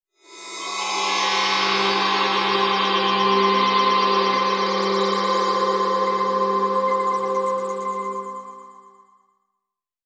chimes.mp3